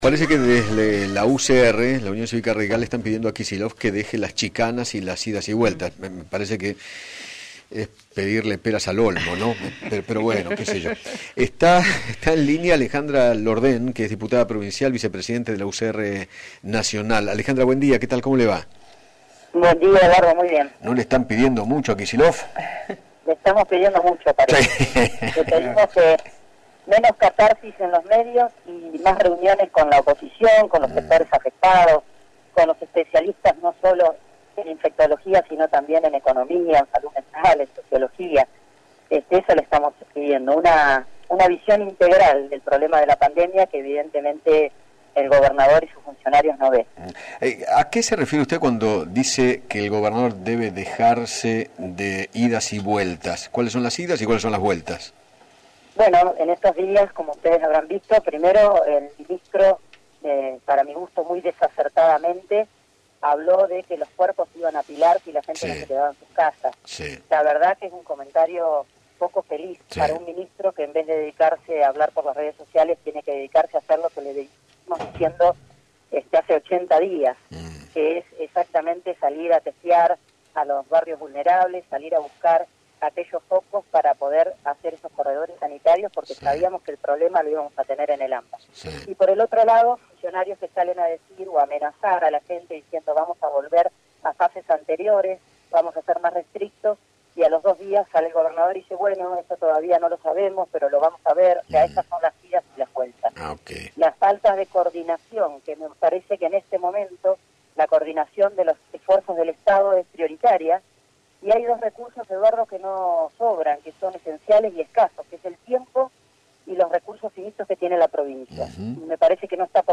Alejandra Lordén, diputada provincial de Buenos Aires por la UCR, dialogó con Eduardo Feinmann sobre el trabajo del gobernador Axel Kicillof frente a la pandemia y manifestó que “le pedimos que tenga una visión integral de la situación”.